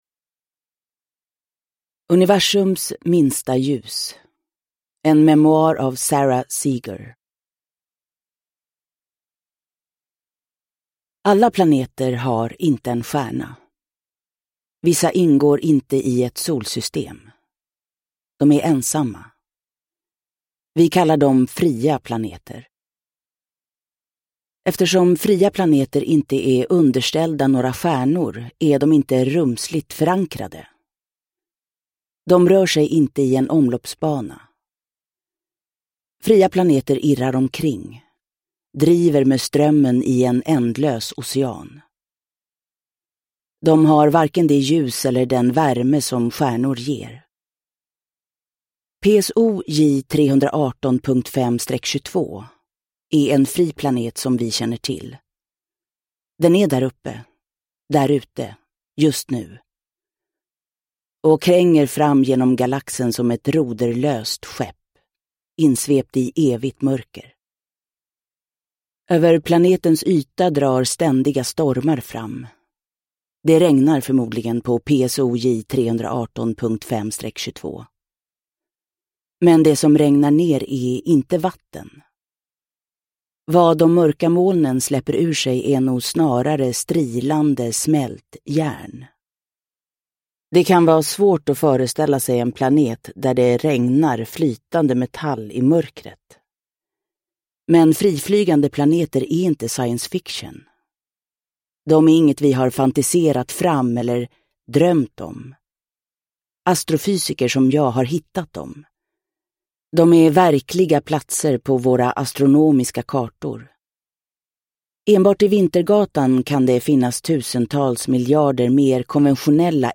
Universums minsta ljus : En memoar – Ljudbok – Laddas ner